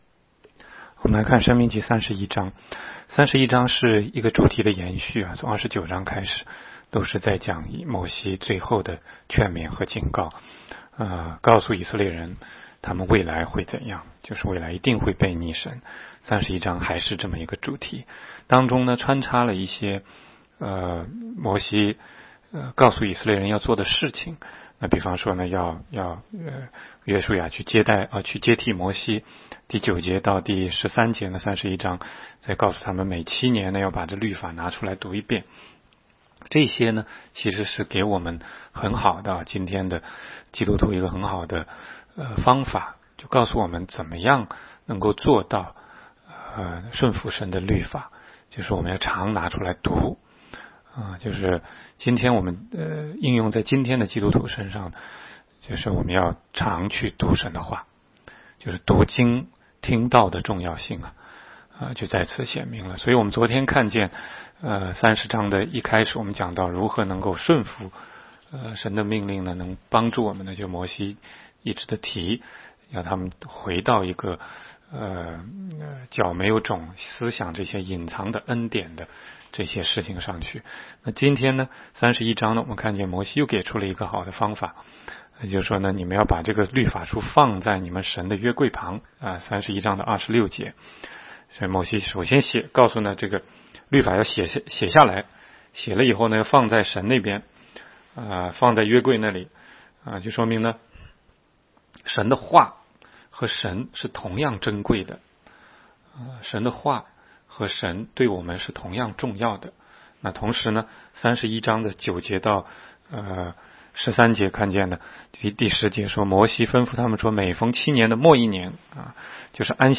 16街讲道录音 - 每日读经-《申命记》31章